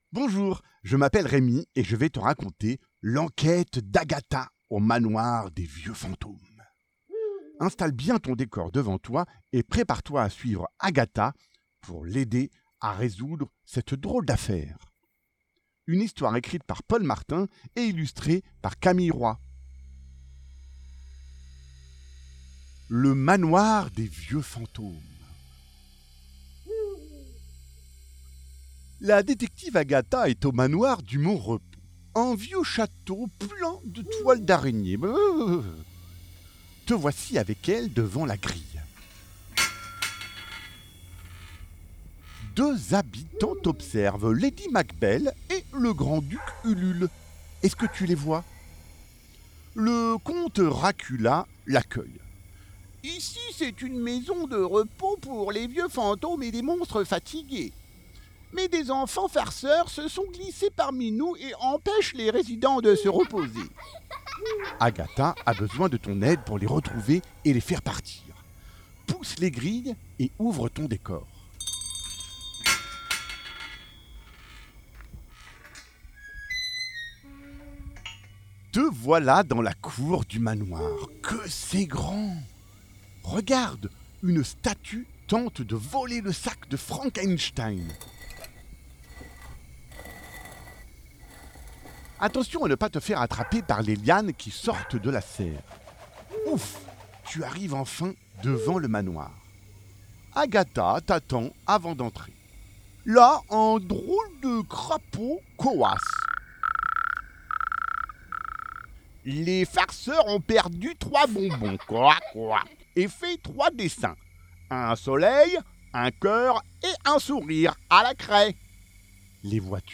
Habillage sonore voix et réalisation